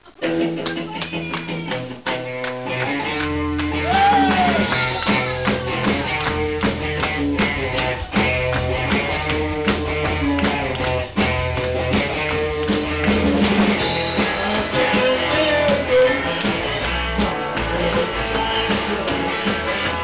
99.６月ミントンハウスでの演奏曲目